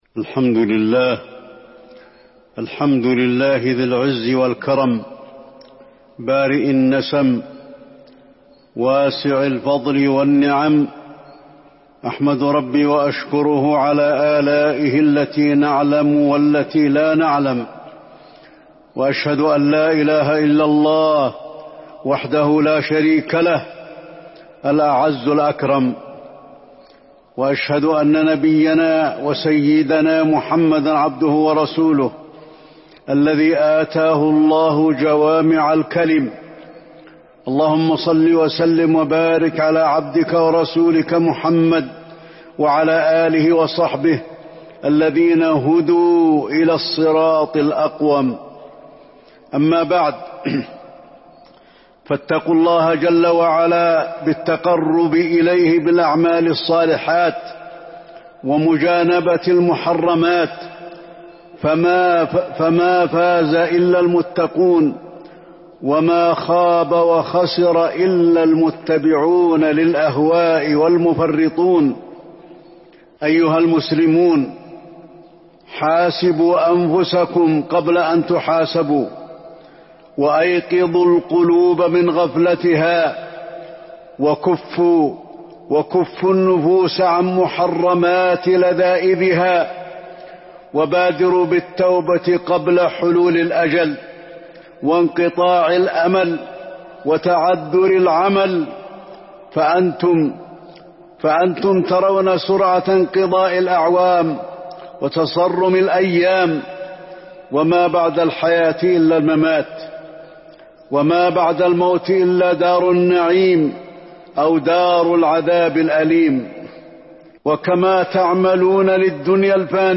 تاريخ النشر ١٢ ربيع الثاني ١٤٤٢ هـ المكان: المسجد النبوي الشيخ: فضيلة الشيخ د. علي بن عبدالرحمن الحذيفي فضيلة الشيخ د. علي بن عبدالرحمن الحذيفي الدين النصيحة